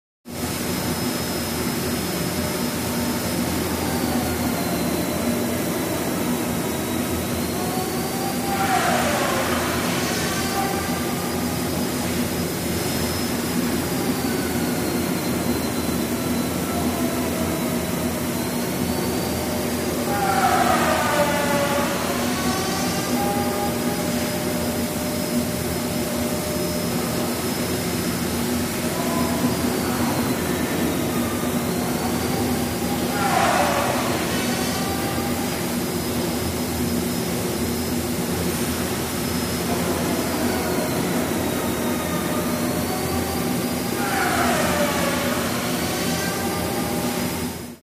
Wood Shop | Sneak On The Lot
Wood Shop Ambience; Heavy Machine And Generator Airy Noise With Medium Perspective Power Saw Cuts Through Wood.